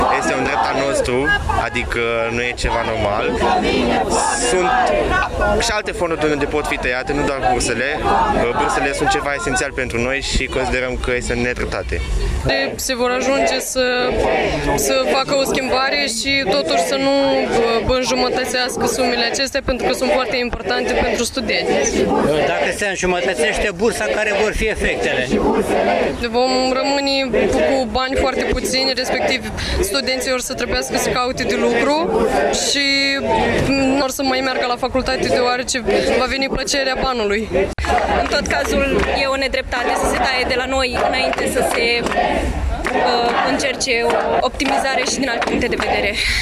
Aproximativ o sută de studenți protestează în Piața Unirii din Iași și critică măsurile din planul noului Guvern ce vizează bursele.
26-iun-ora-11-vox-studenti.mp3